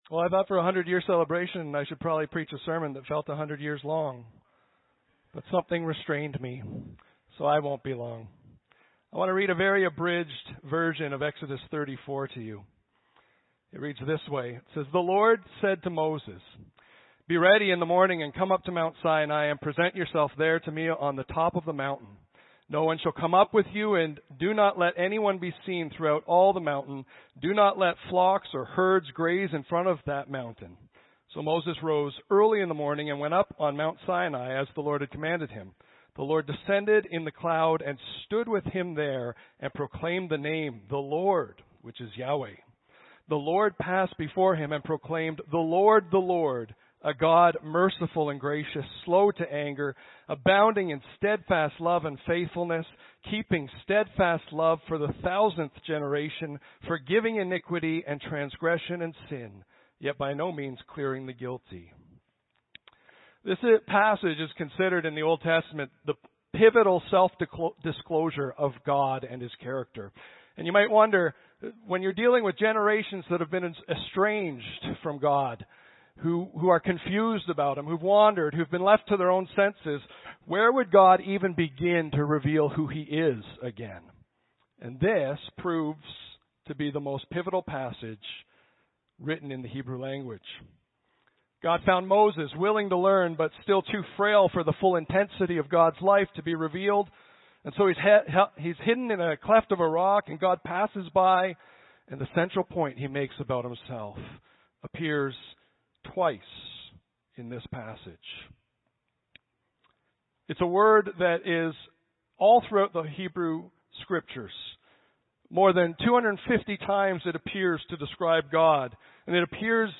Audio Sermons - Clive Baptist Church
100th Anniversary Service
100th_anniversary_celebration.mp3